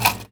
R - Foley 178.wav